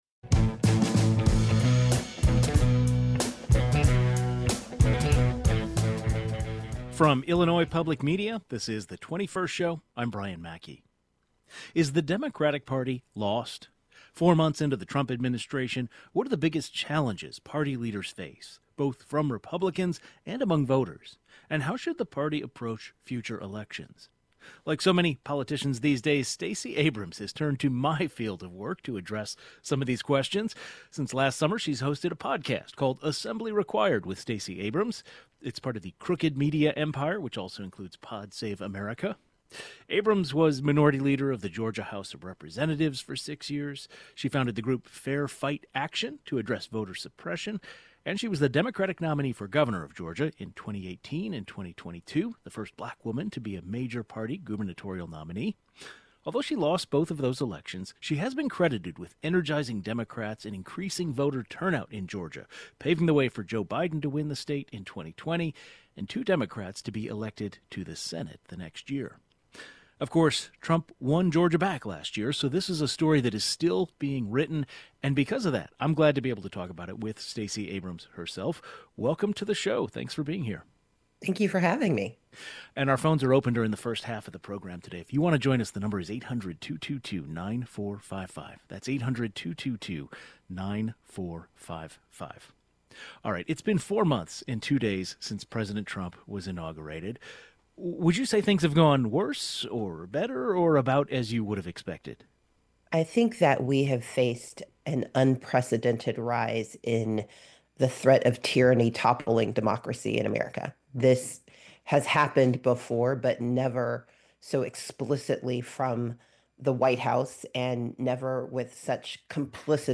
Political leader Stacey Abrams discusses strategies for Dems to win voters back